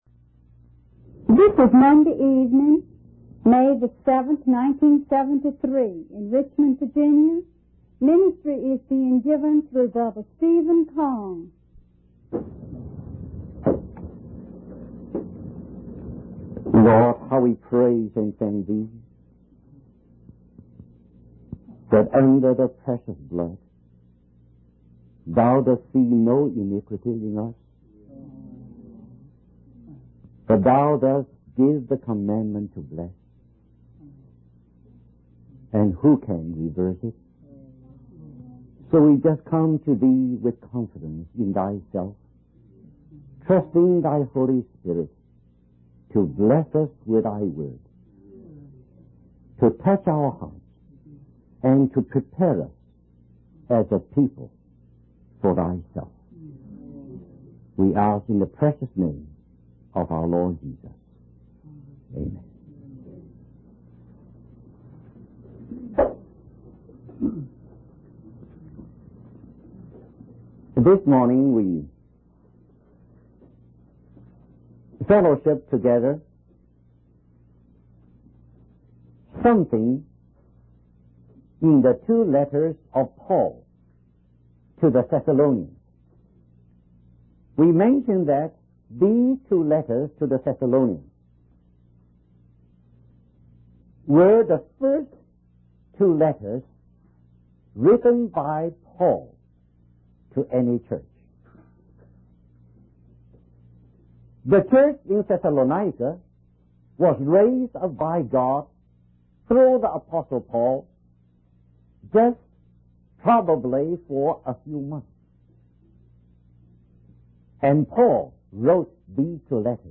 In this sermon, the preacher emphasizes the coming judgment of God upon the earth.